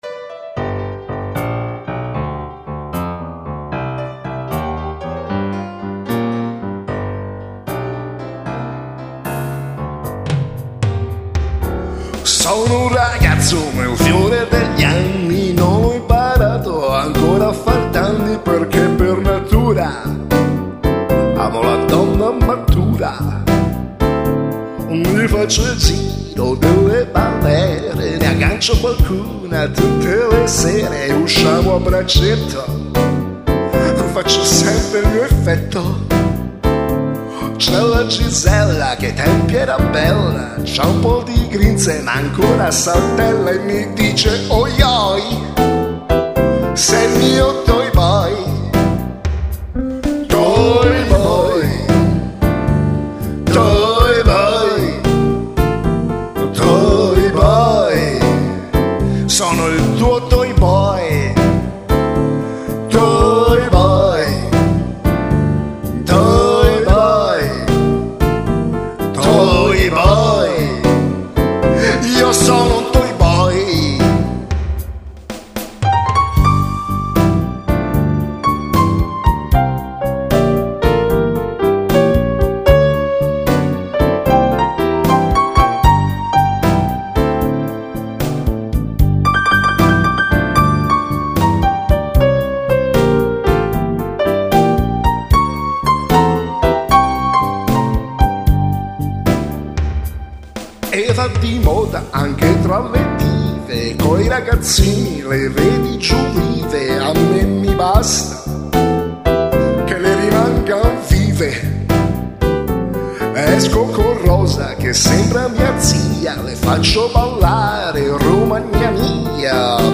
Un blues trascinante